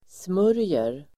Uttal: [sm'ör:jer]